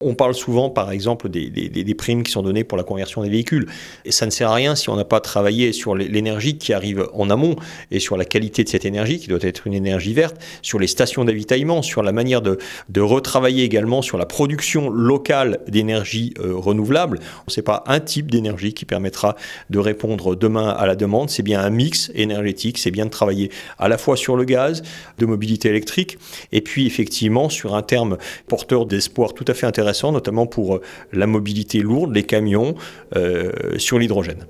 Eric Fournier, maire de Chamonix et Conseiller régional spécial chargé de l’air.